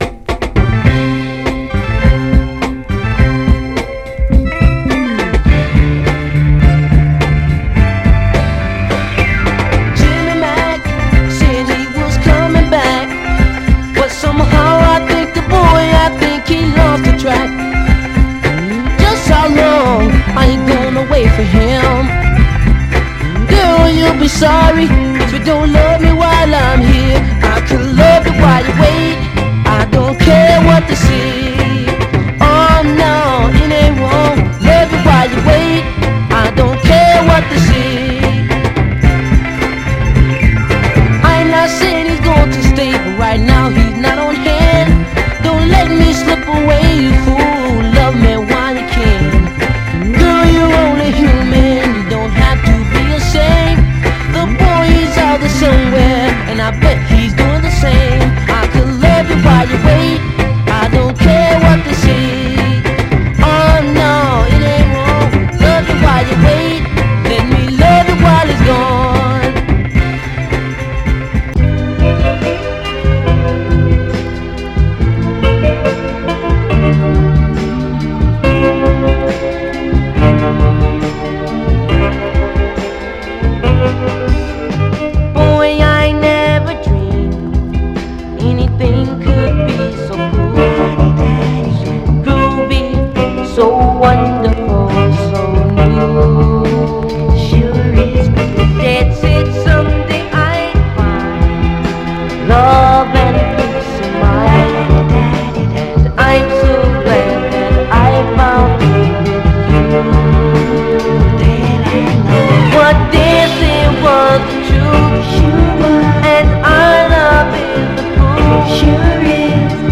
双子だらけのキッズ・ソウル・グループ
軽快クロスオーヴァーなミッド・ソウル・ダンサー
甘茶メロウなバブルバム・ソウル
※試聴音源は実際にお送りする商品から録音したものです※